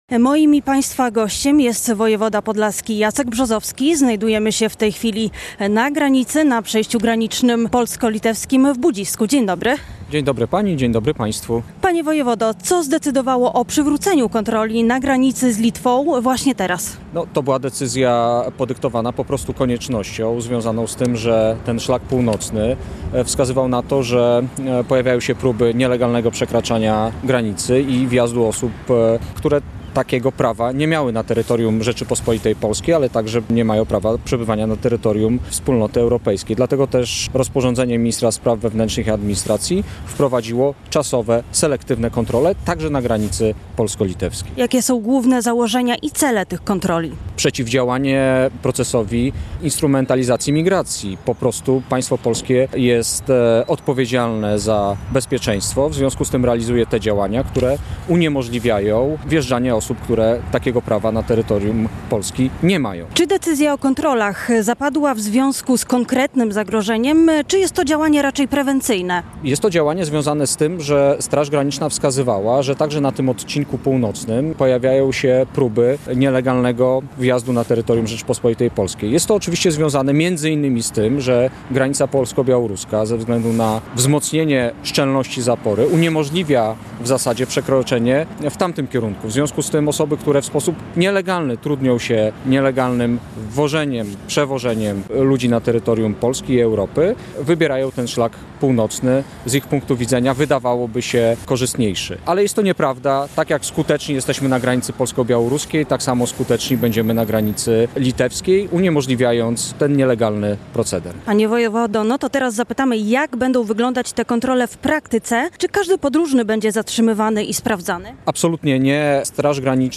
Jak wyglądają kontrole, co oznaczają dla mieszkańców regionu i kierowców - na przejściu granicznym w Budzisku, z wojewodą podlaskim Jackiem Brzozowski rozmawiała